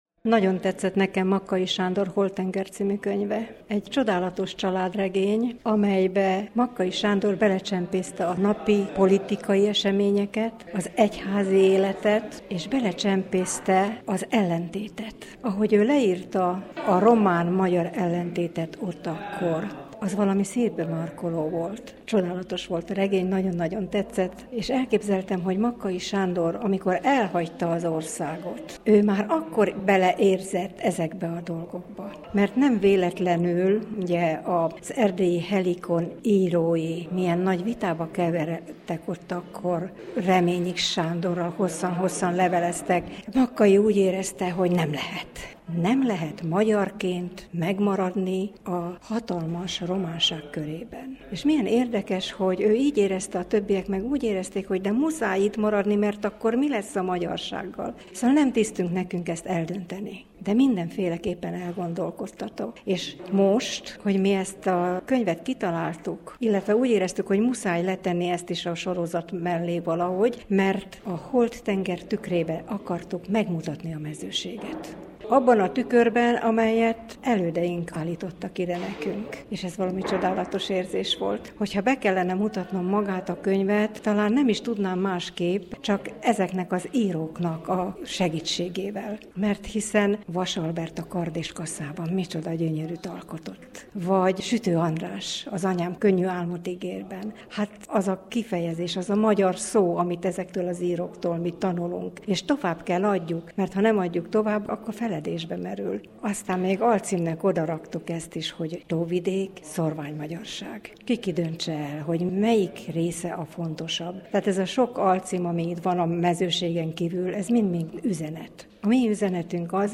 interjújában.